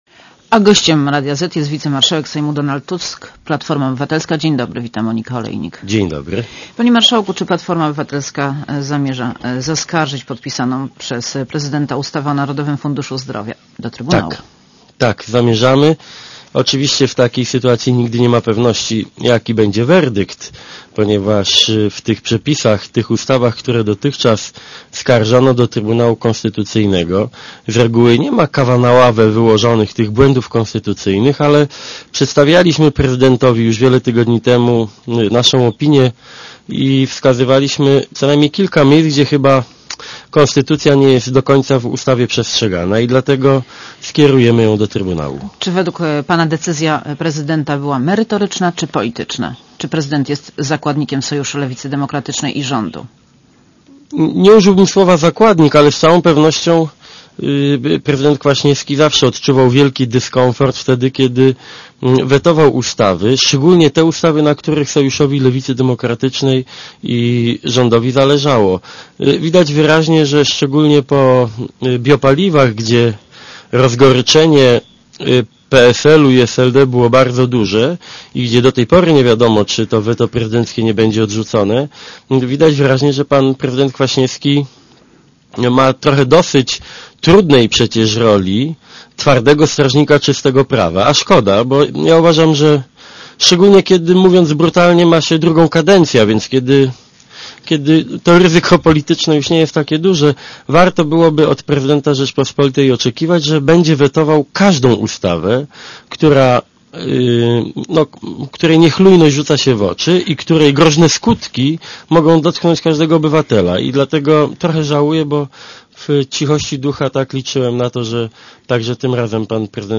Monika Olejnik rozmawia z wicemarszałkiem Sejmu Donaldem Tuskiem